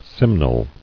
[sim·nel]